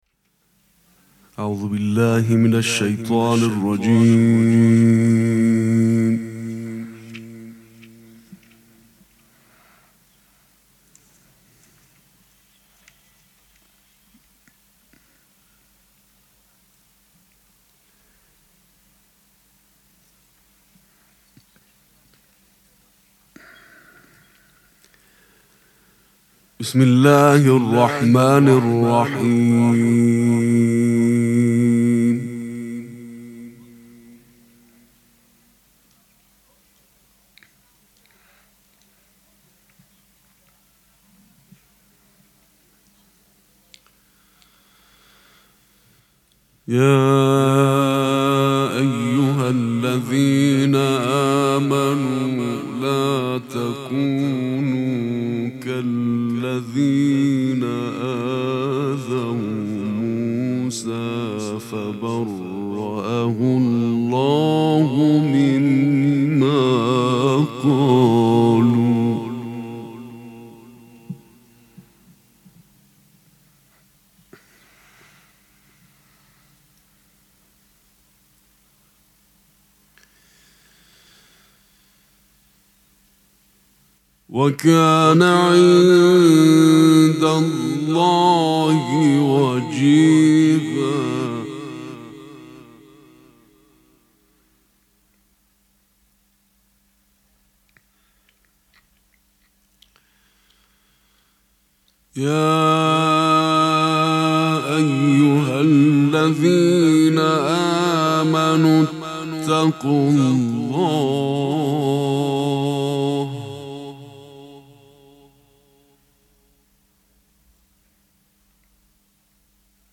قرائت قرآن کریم
شب پنجم مراسم هشت شب عاشقی ماه رمضان
سبک اثــر قرائت قرآن